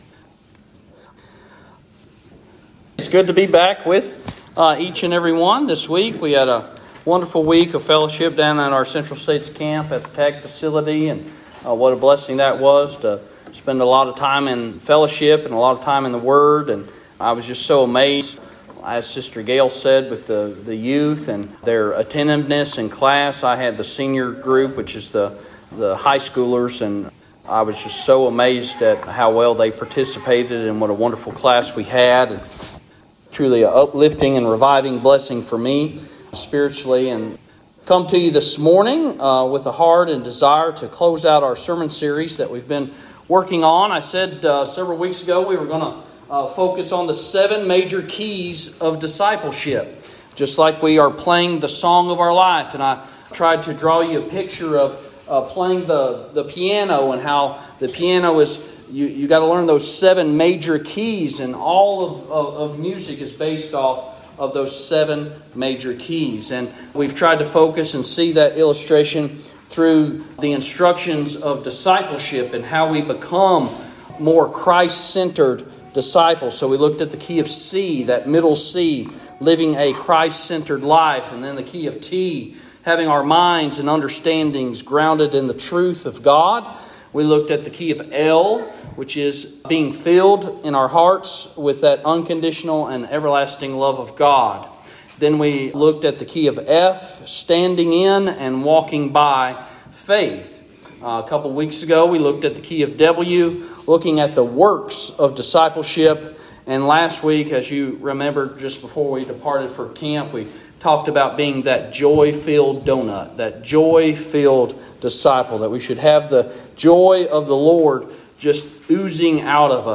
Today's Sermon